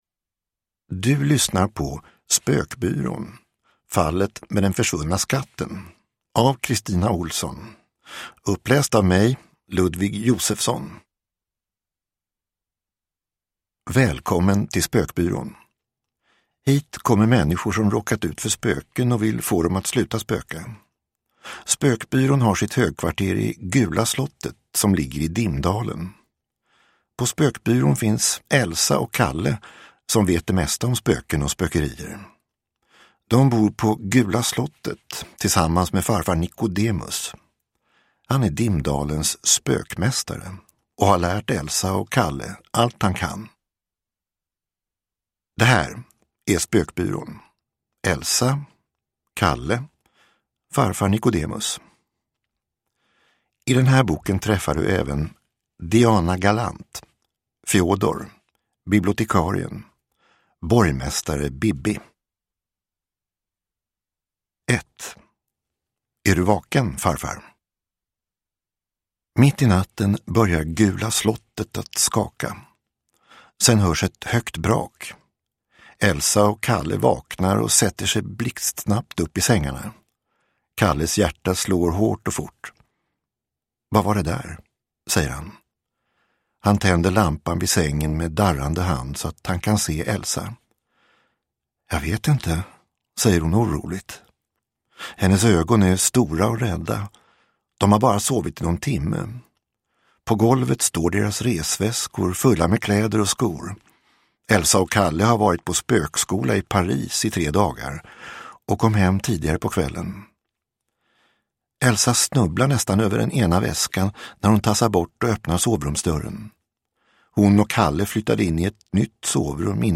Spökbyrån. Fallet med den försvunna skatten – Ljudbok